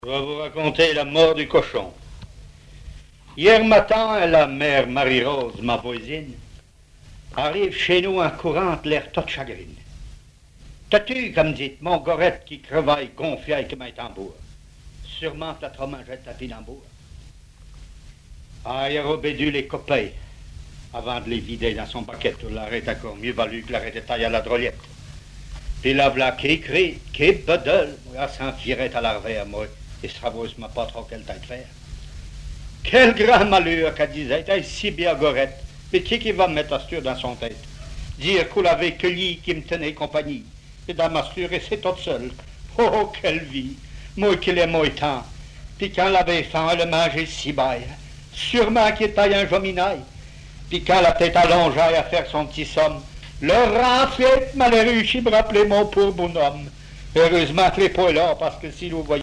Genre sketch
textes en patois et explications sur la prononciation
Catégorie Récit